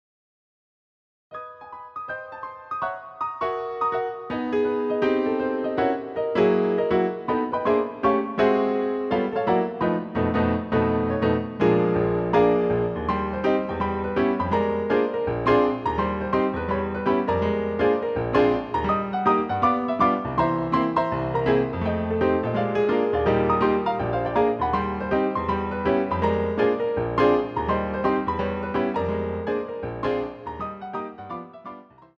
CD quality digital audio Mp3 file
using the stereo sampled sound of a Yamaha Grand Piano.